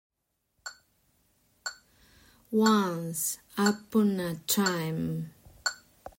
once__upon_a_time2.mp3